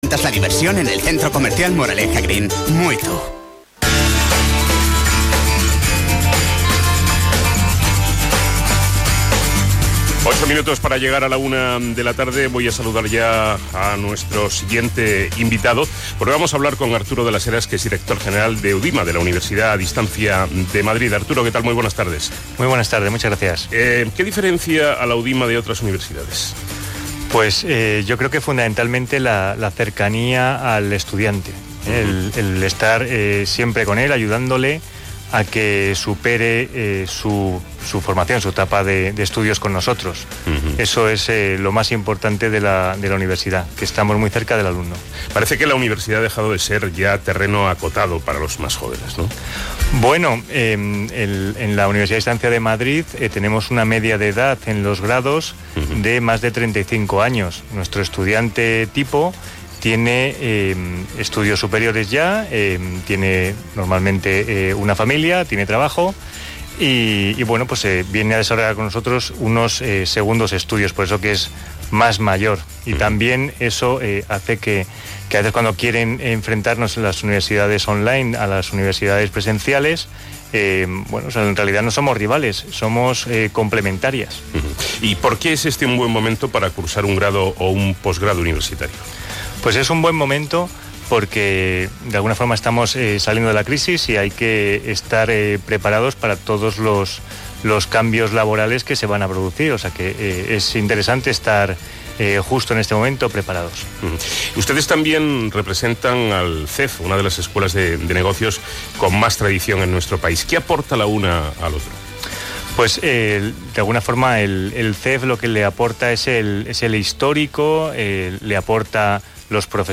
OCR FM MADRID ENTREVISTA UDIMA 22 SEPT 16.mp3